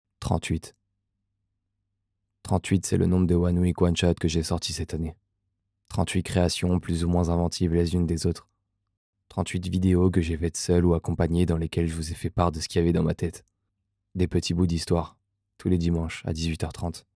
Voix off 2
21 - 38 ans - Baryton